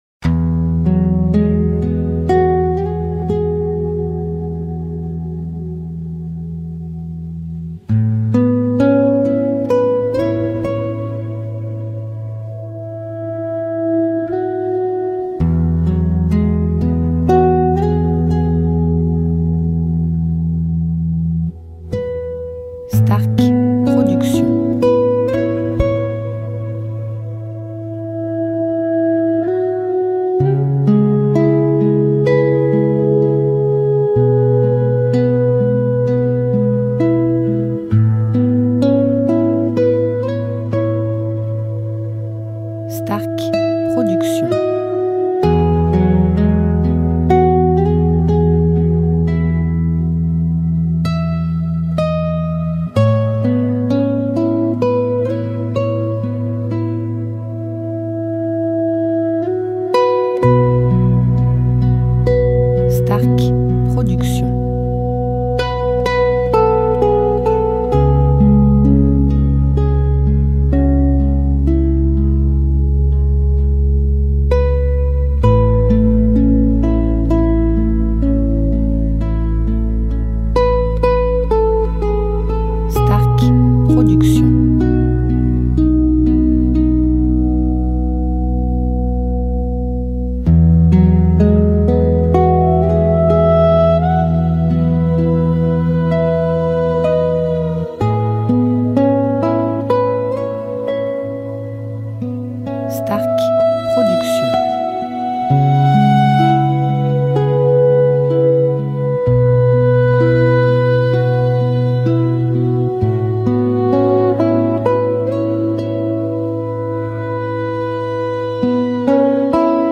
style Californien durée 1 heure